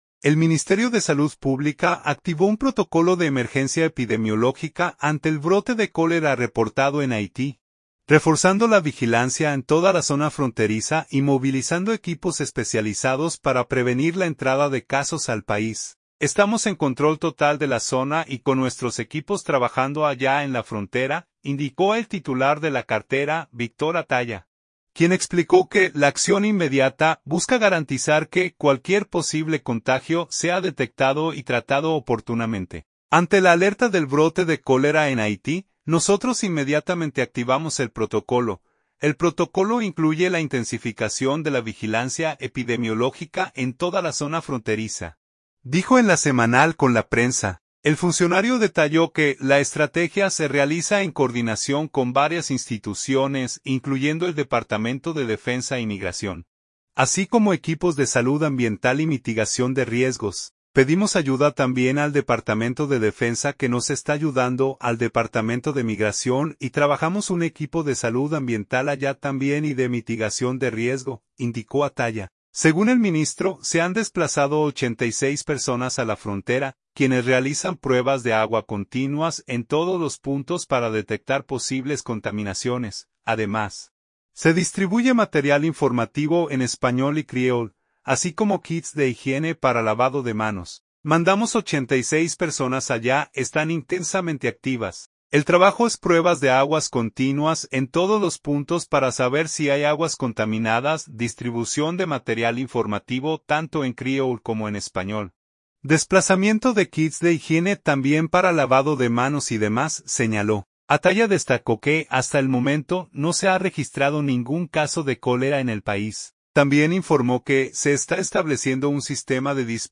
“Ante la alerta del brote de cólera en Haití, nosotros inmediatamente activamos el protocolo. El protocolo incluye la intensificación de la vigilancia epidemiológica en toda la zona fronteriza”, dijo en LA Semanal con la Prensa.